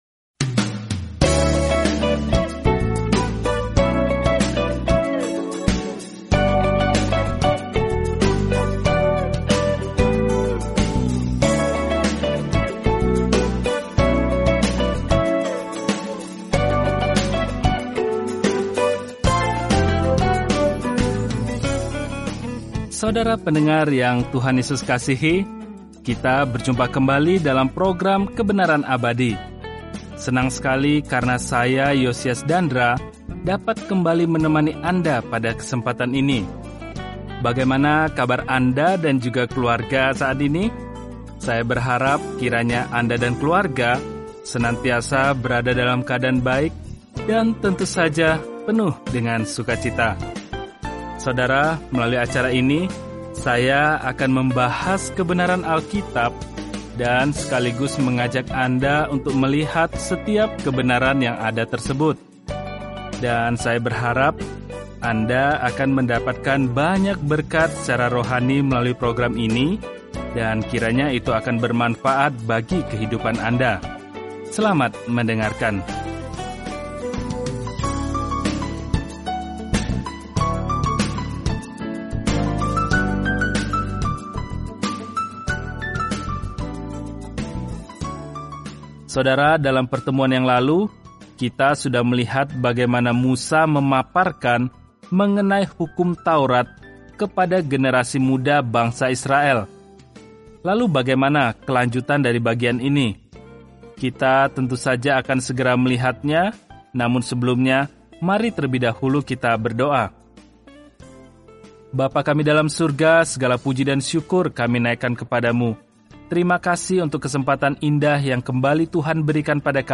Firman Tuhan, Alkitab Ulangan 5 Hari 3 Mulai Rencana ini Hari 5 Tentang Rencana ini Ulangan merangkum hukum baik Allah dan mengajarkan bahwa ketaatan adalah tanggapan kita terhadap kasih-Nya. Telusuri Ulangan setiap hari sambil mendengarkan pelajaran audio dan membaca ayat-ayat tertentu dari firman Tuhan.